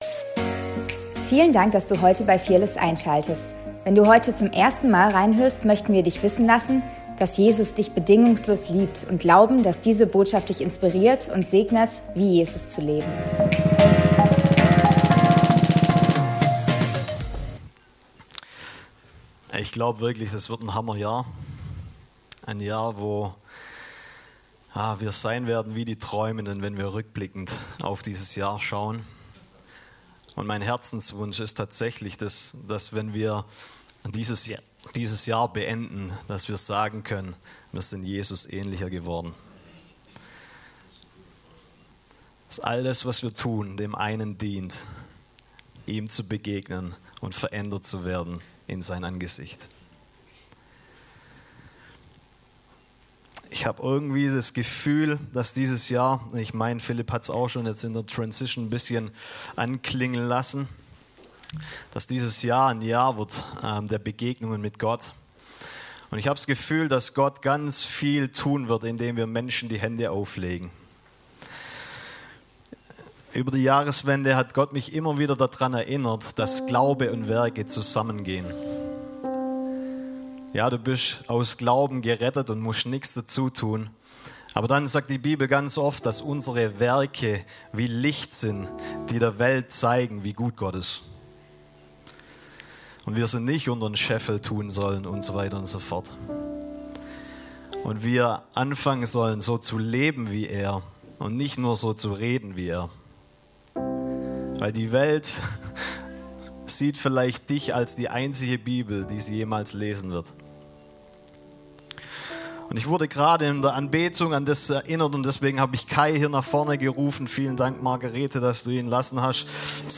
Predigt vom 11.01.2026